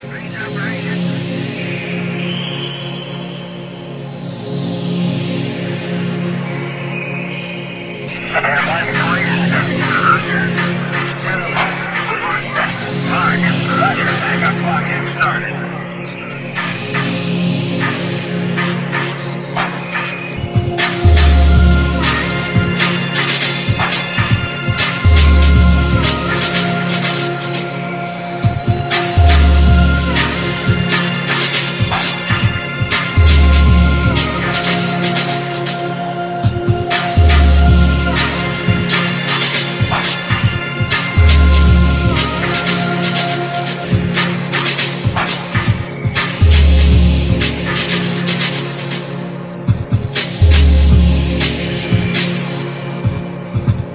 Industrial / ebm / electro / ethnodark- compilation